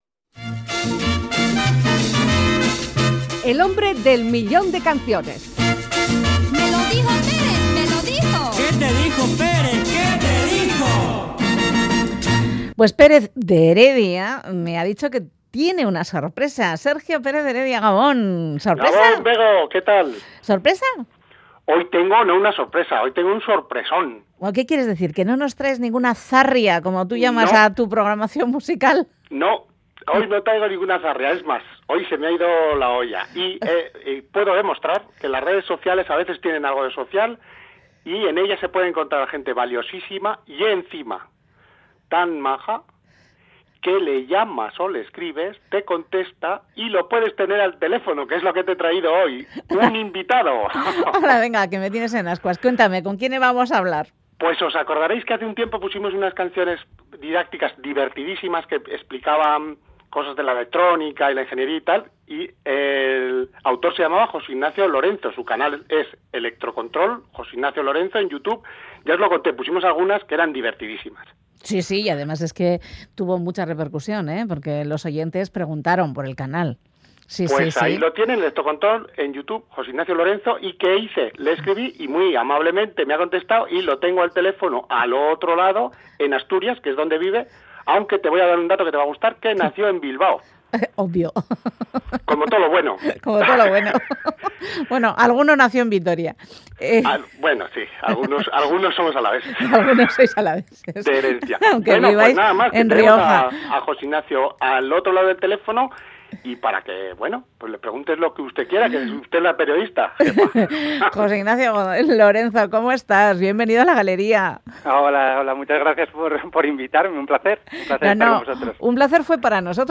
explica un motor cantando.